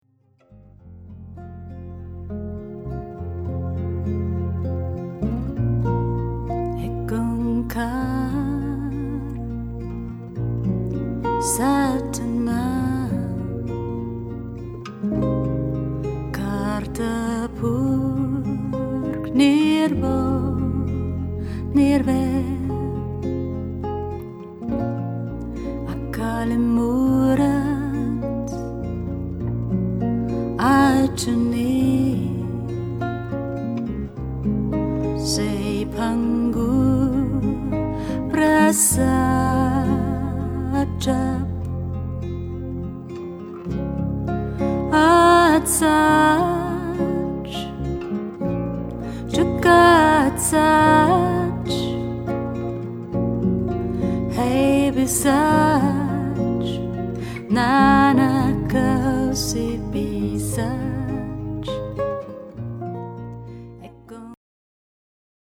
Worldmusic / Mantra.